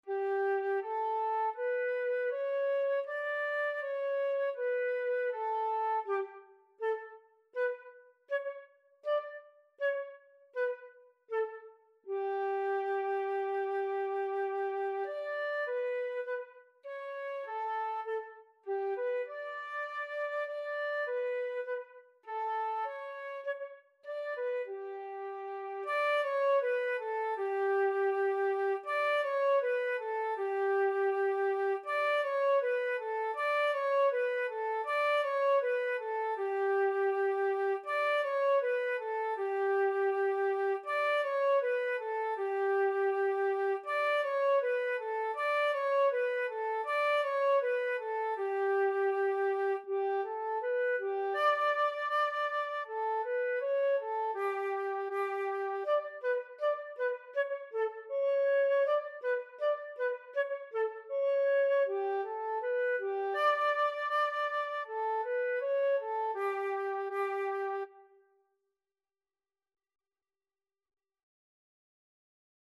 4/4 (View more 4/4 Music)
G5-D6
Beginners Level: Recommended for Beginners
Flute  (View more Beginners Flute Music)
Classical (View more Classical Flute Music)